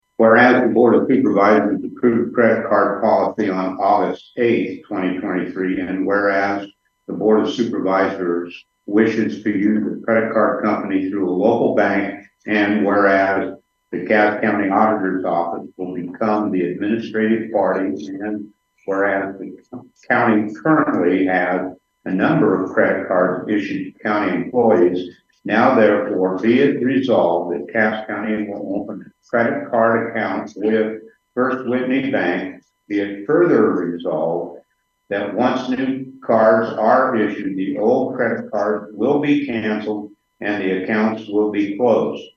Board Chairman Steve Baier read the resolution….